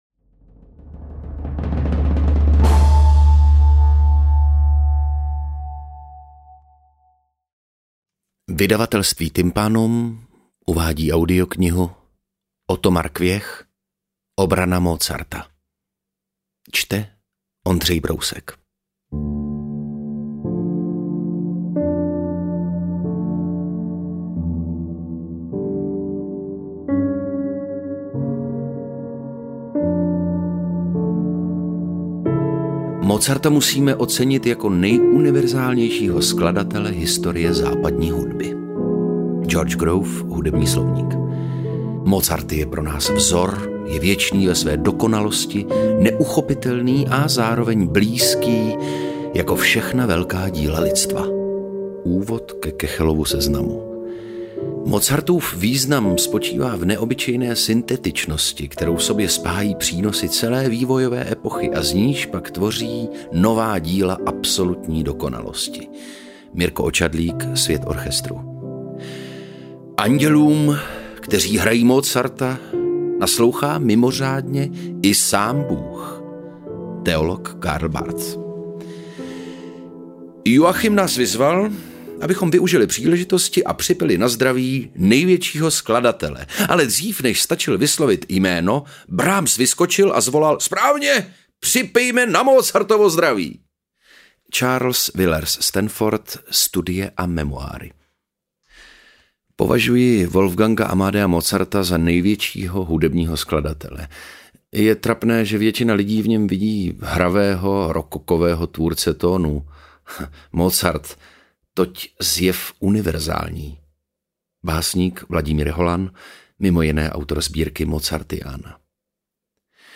Interpret:  Otakar Brousek ml.
AudioKniha ke stažení, 25 x mp3, délka 3 hod. 26 min., velikost 187,1 MB, česky